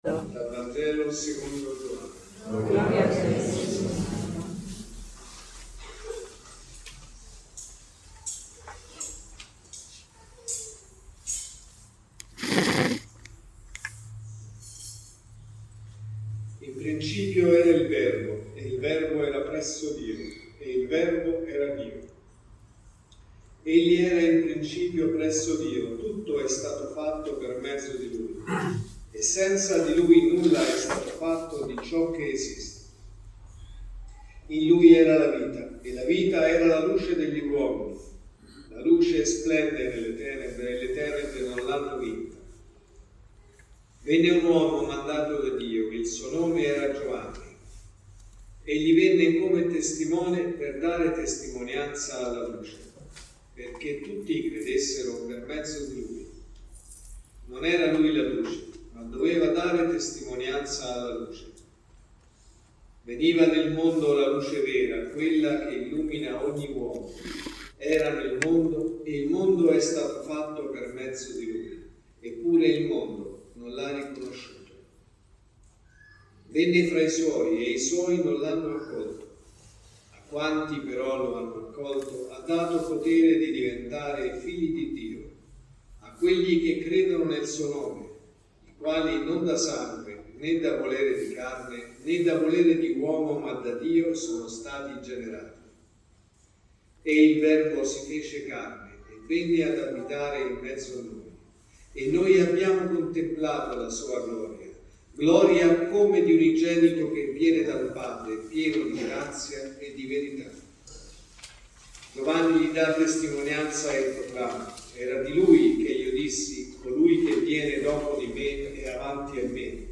(Messa del mattino e della sera) | Omelie LETTURE: Vangelo, Prima lettura e Seconda lettura Dal Vangelo secondo Giovanni (Gv 1,1-18) In principio era il Verbo, e il Verbo era presso Dio e il Verbo era Dio.